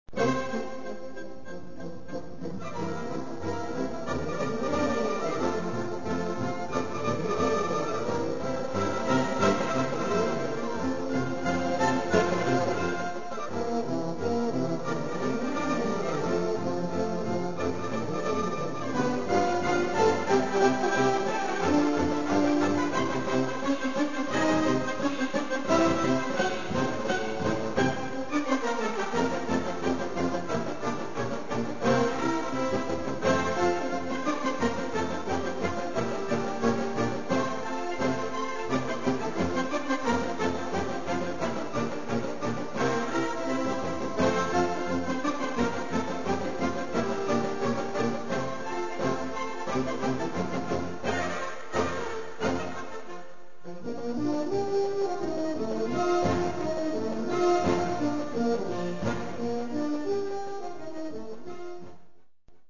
Gattung: Schnellpolka, op. 324
Besetzung: Blasorchester